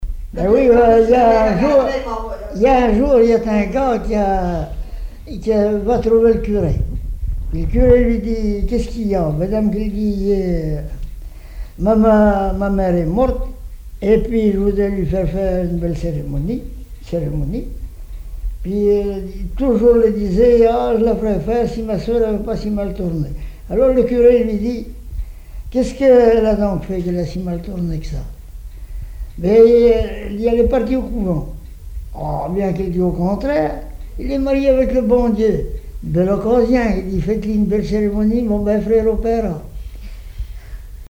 Genre sketch
Catégorie Récit